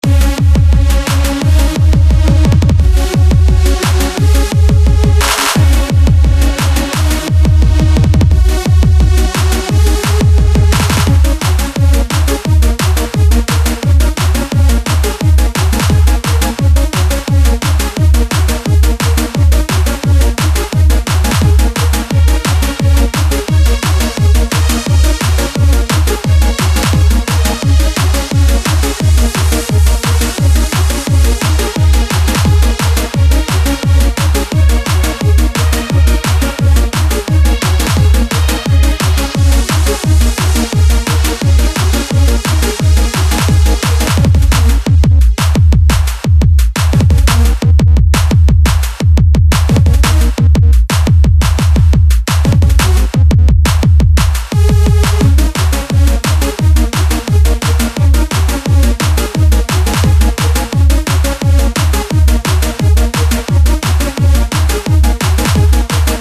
You used already some parts with such a funny melody, so imo it isn't much that should be changed. nonetheless, good work.